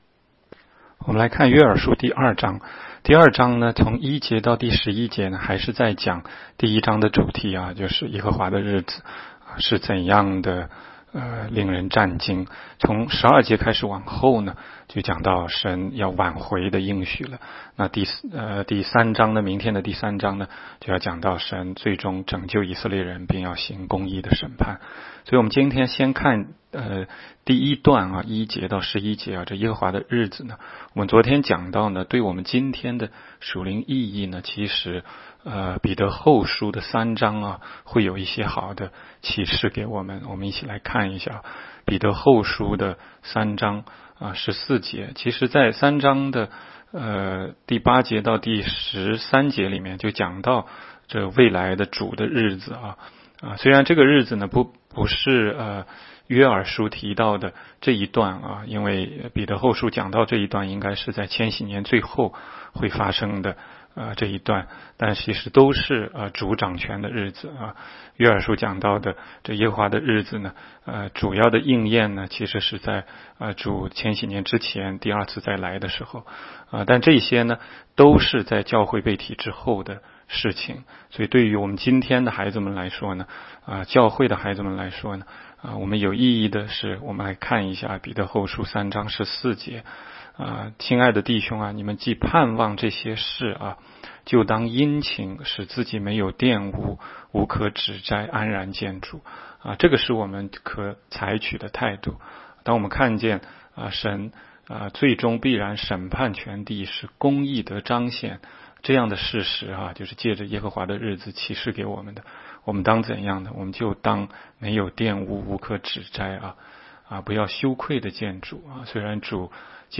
16街讲道录音 - 每日读经 -《约珥书》2章
每日读经